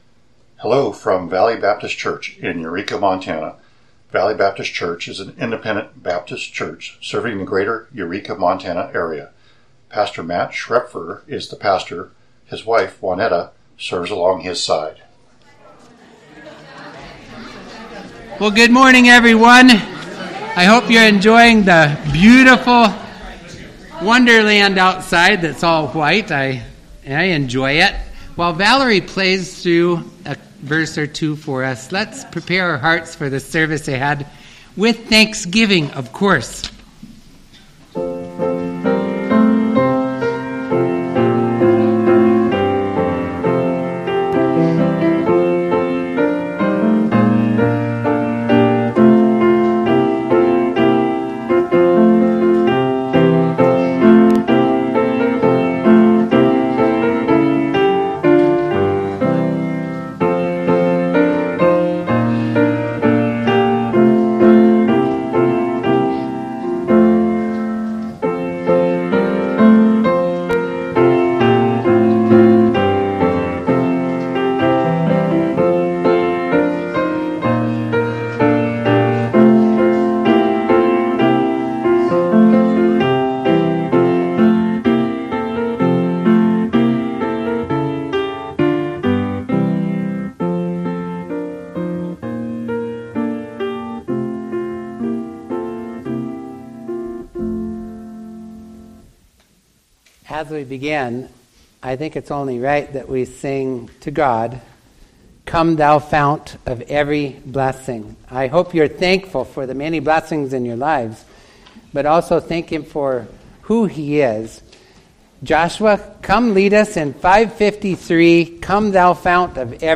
This Sunday’s Sermon: